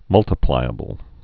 (mŭltə-plīə-bəl) also mul·ti·plic·a·ble (-plĭkə-bəl)